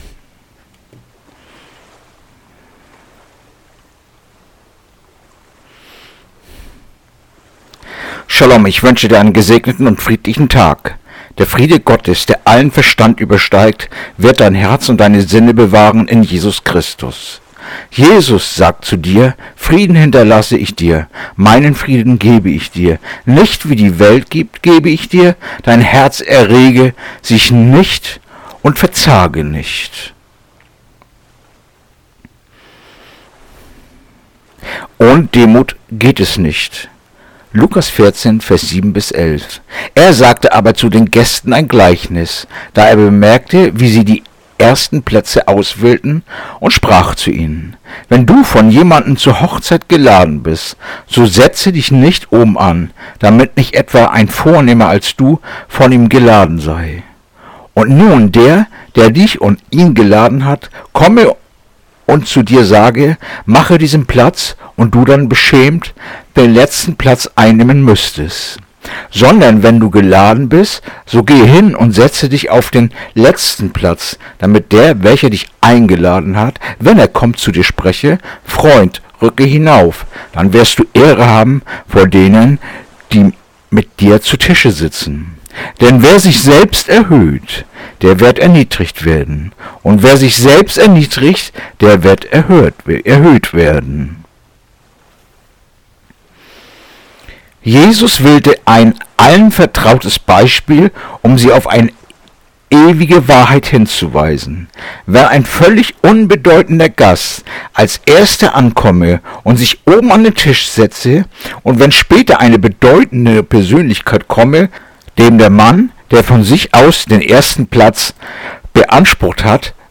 Andacht-vom-08.-Juli-Lukas-14-7-11